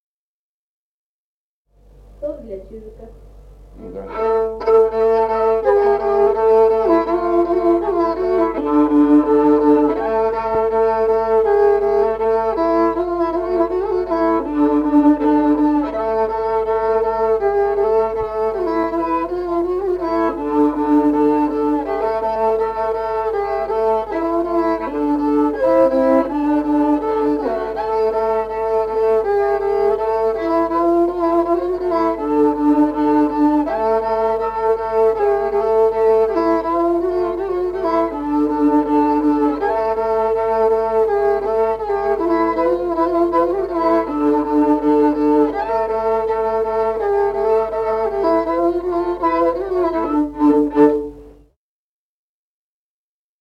Музыкальный фольклор села Мишковка «Чижик», партия 2-й скрипки.